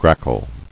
[grack·le]